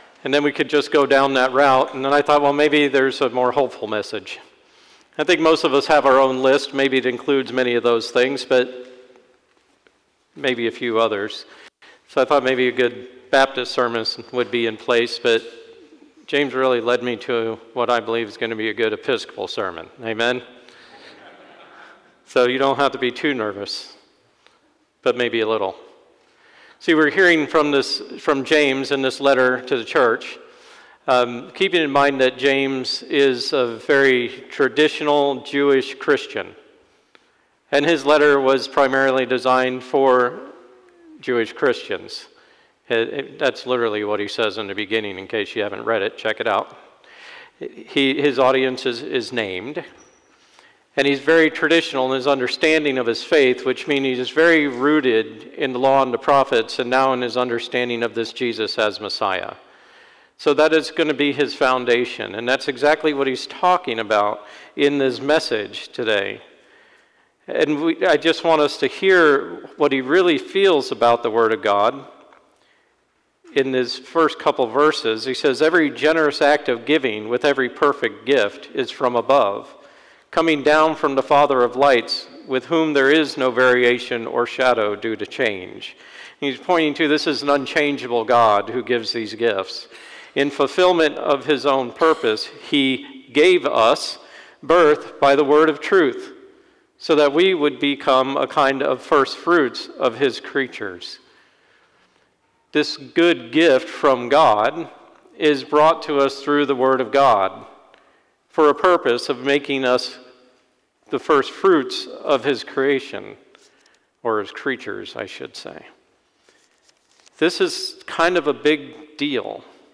Sermon 9/1/24 Fifteenth Sunday after Pentecost